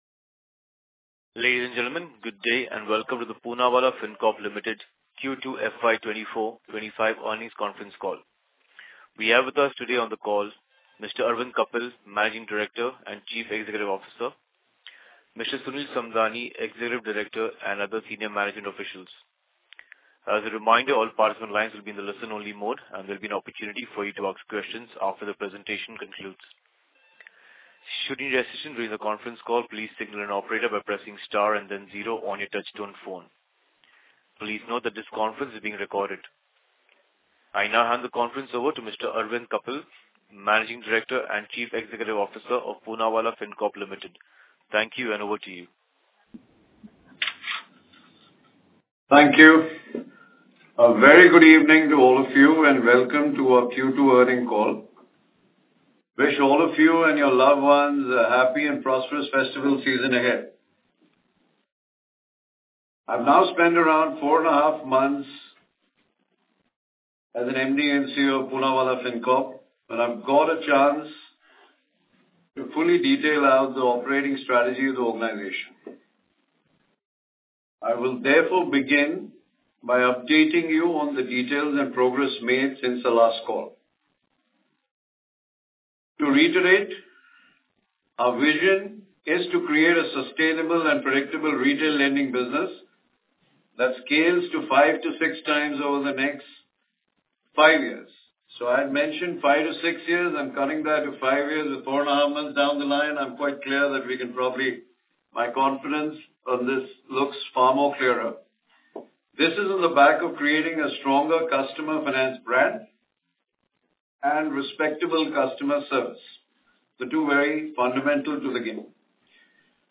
Concalls
Q2FY25-Earnings-Call-Recordings.mp3